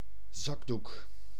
Ääntäminen
Synonyymit tire-jus Kleenex Ääntäminen France (Paris): IPA: [ɛ̃ mu.ʃwaʁ] Tuntematon aksentti: IPA: /muʃ.waʁ/ Haettu sana löytyi näillä lähdekielillä: ranska Käännös Ääninäyte Substantiivit 1. zakdoek {m} Suku: m .